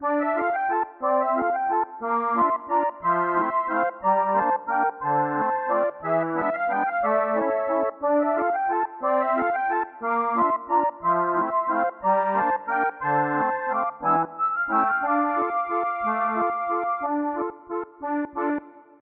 パイプオルガンによる短いながら重厚なイメージの曲です